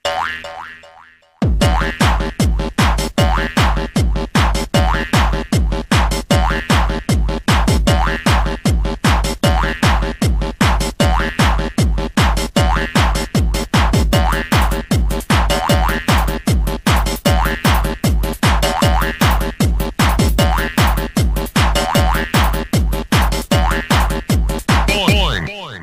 Electronica
tone , ring , music , trance , techno , rave ,